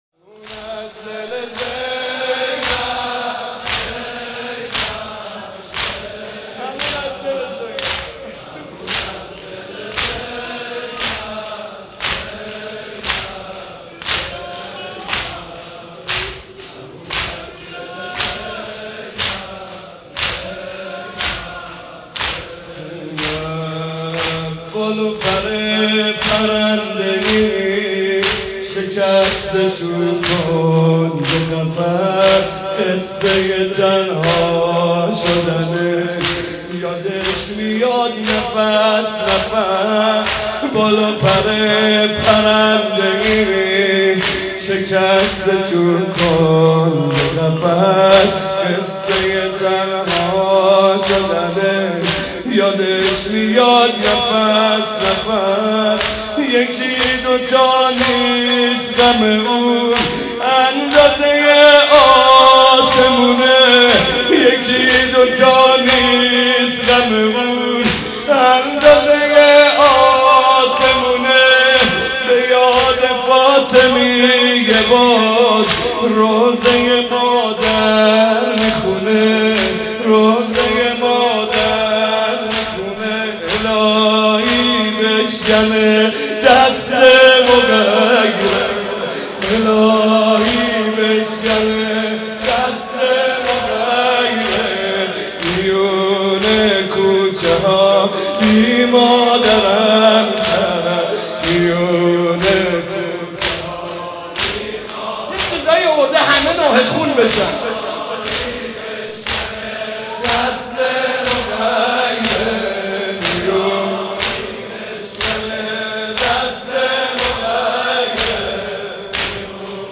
مناسبت : وفات حضرت زینب سلام‌الله‌علیها
مداح : محمدرضا طاهری قالب : زمینه